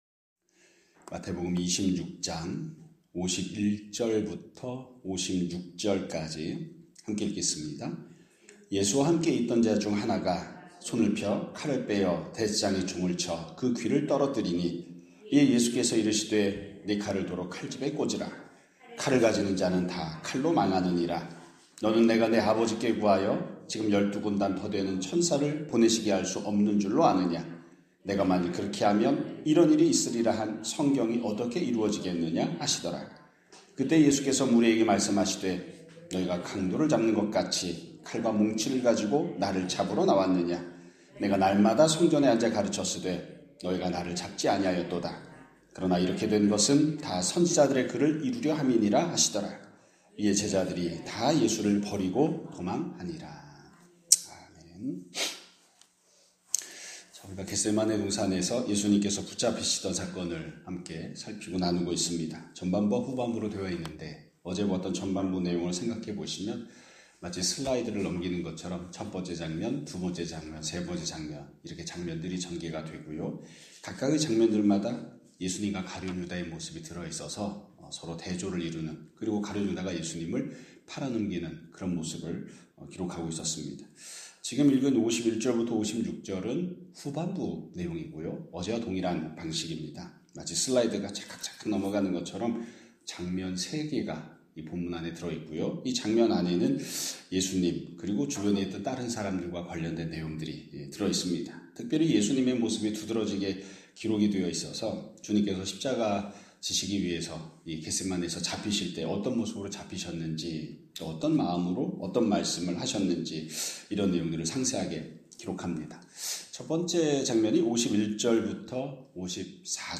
2026년 4월 8일 (수요일) <아침예배> 설교입니다.